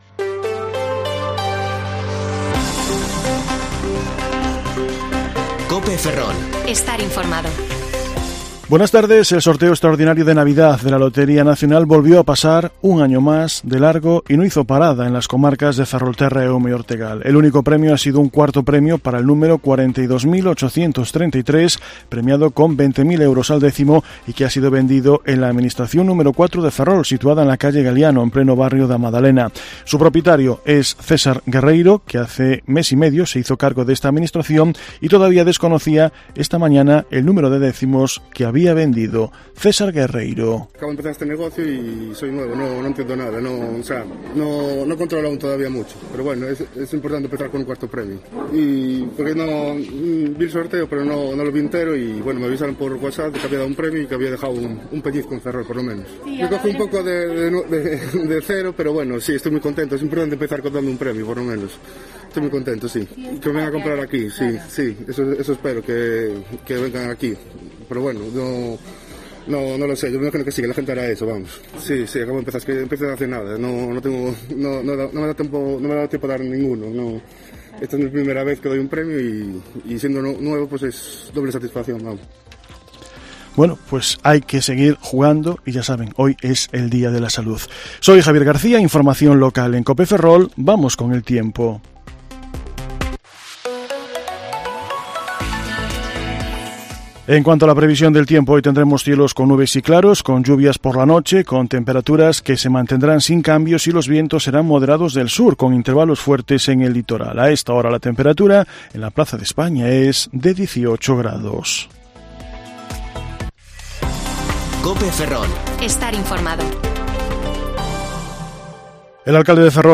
Informativo Mediodía COPE Ferrol 22/12/2021 (De 14,20 a 14,30 horas)